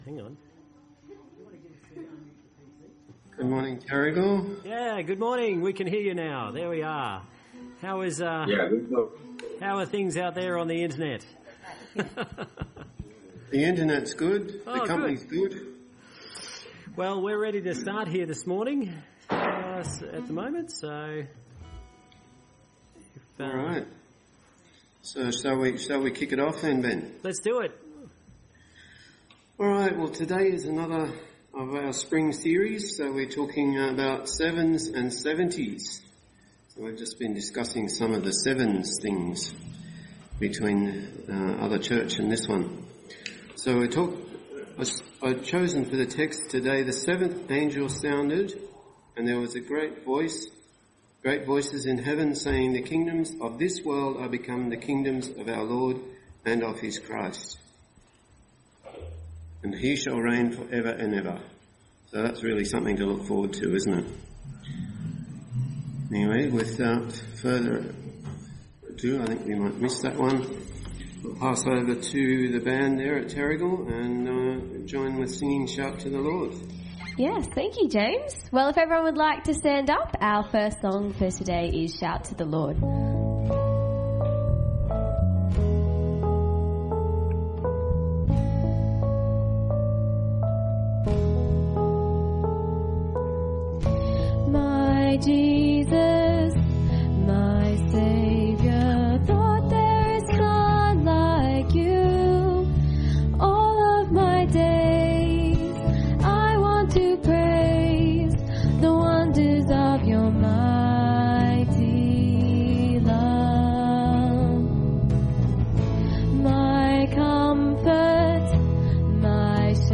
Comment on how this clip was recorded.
Spring Series Passage: Revelation 11 Service Type: Sunday Church Download Files Notes Topics